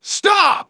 synthetic-wakewords
ovos-tts-plugin-deepponies_Engineer_en.wav